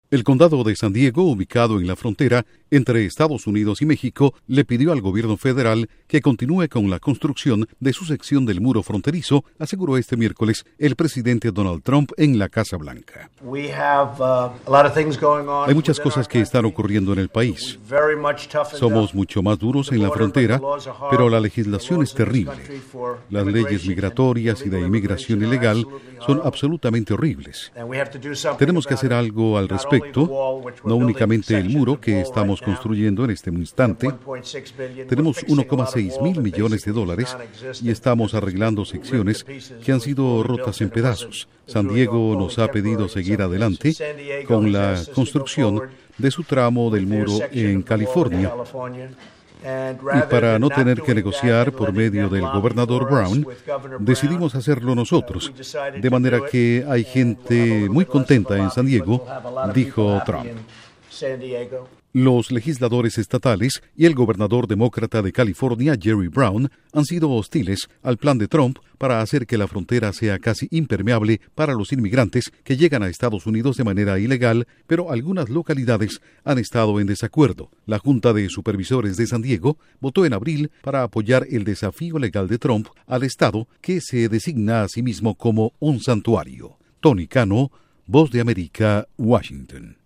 Trump: El condado de San Diego pide al Gobierno Federal de Estados Unidos avance con la construcción del muro fronterizo. Informa desde la Voz de América en Washington